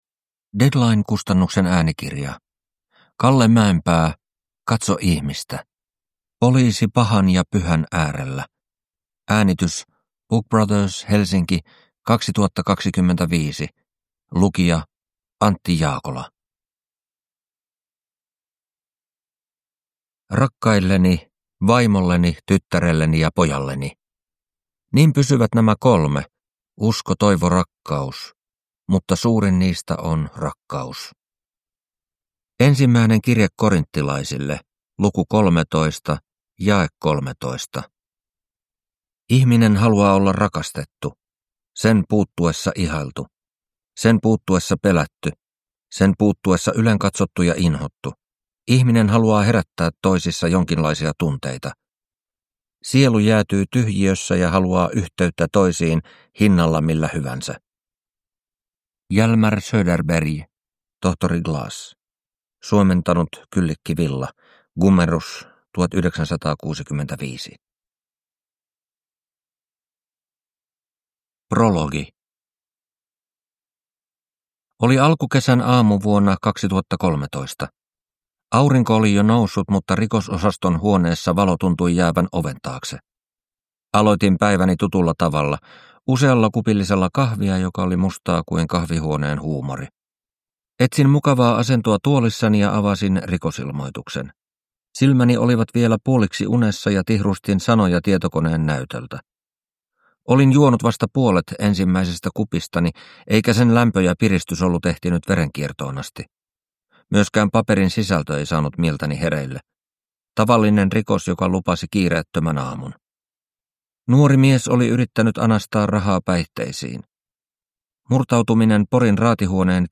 Katso ihmistä – Ljudbok
• Ljudbok